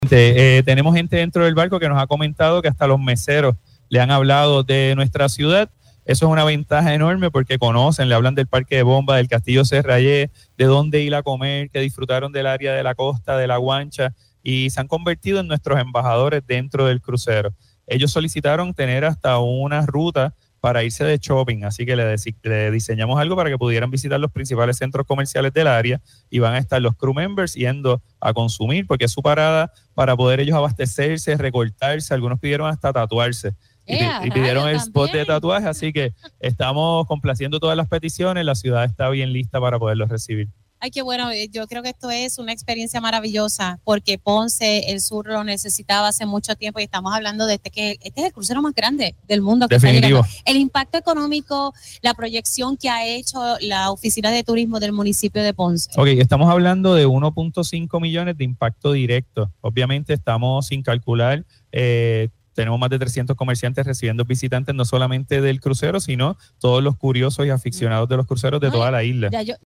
Obviamente, estamos sin calcular, tenemos más de trescientos comerciantes recibiendo visitantes, no solamente del crucero, sino todos los curiosos y aficionados de los cruceros de toda la isla“, indicó en entrevista para Pega’os en la Mañana, en una transmisión en directo desde el puerto sureño para recibir a los pasajeros traídos por Royal Caribbean.